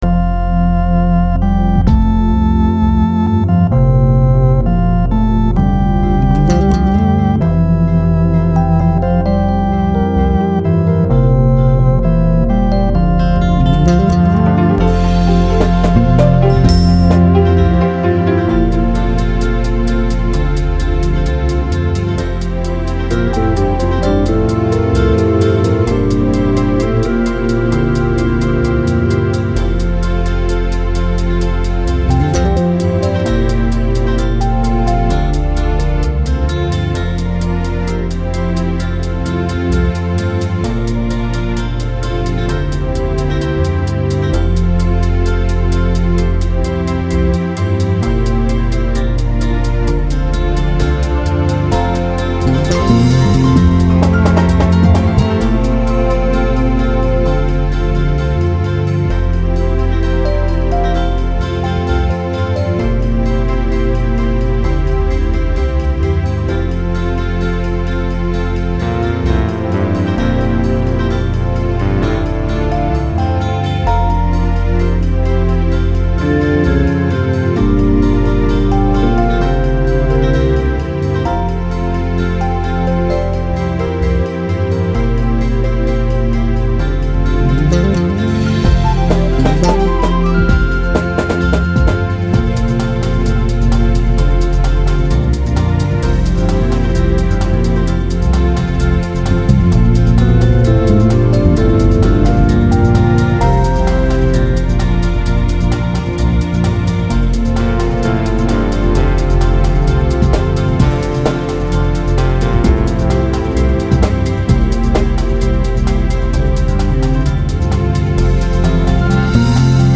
Light Rock,Beat